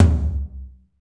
Index of /90_sSampleCDs/Northstar - Drumscapes Roland/DRM_Techno Rock/TOM_F_T Toms x
TOM F T L02L.wav